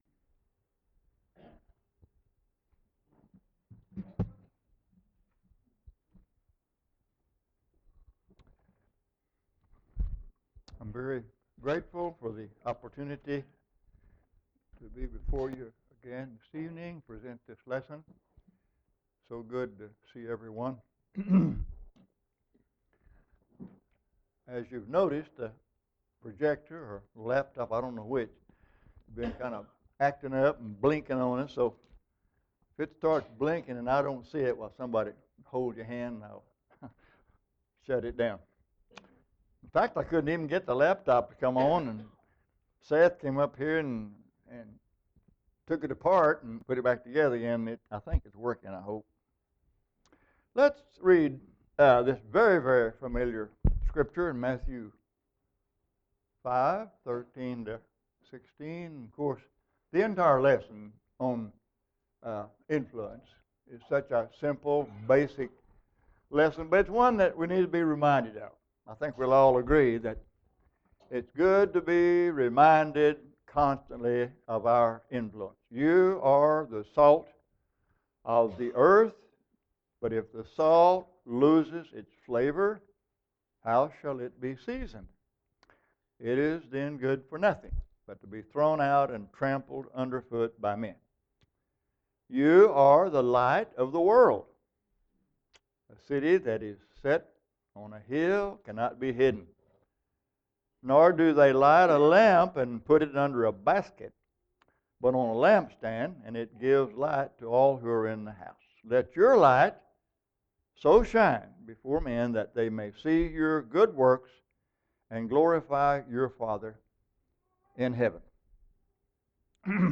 Sermon MP3s – PowerPoint – Keynote – Outlines